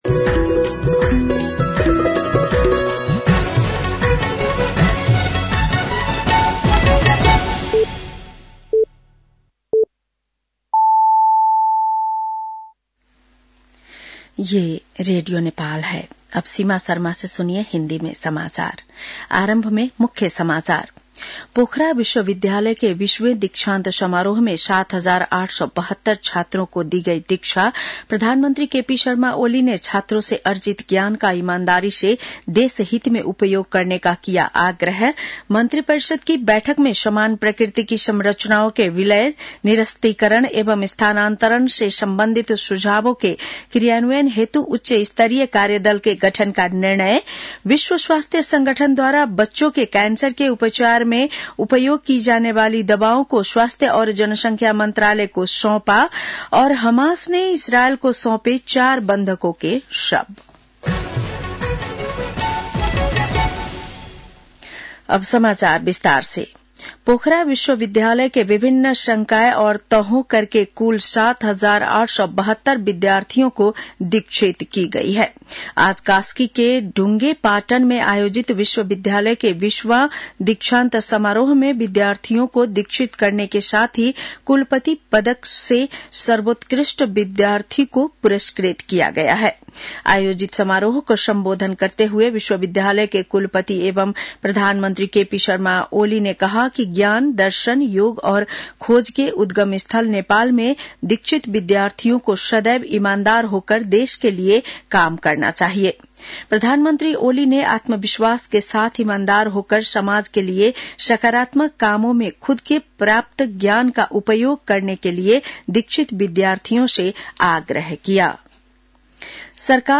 बेलुकी १० बजेको हिन्दी समाचार : ९ फागुन , २०८१
10-PM-Hindi-NEWS-.mp3